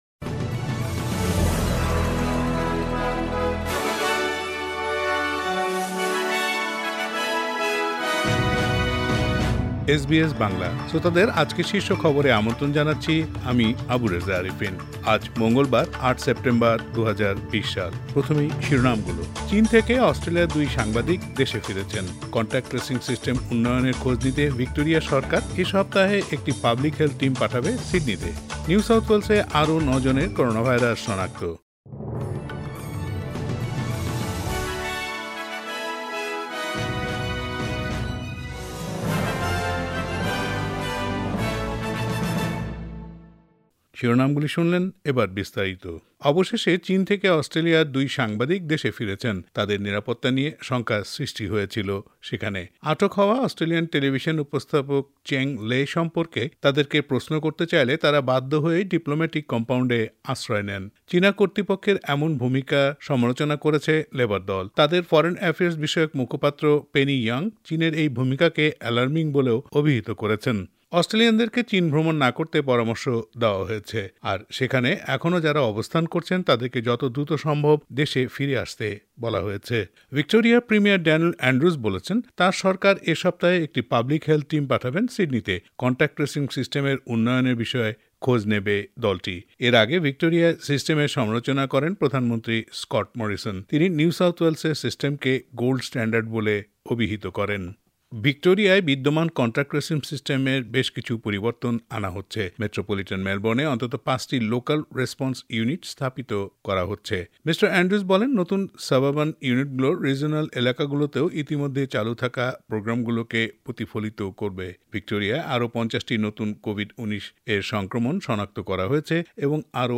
এসবিএস বাংলা শীর্ষ খবর :০৮ সেপ্টেম্বর ,২০২০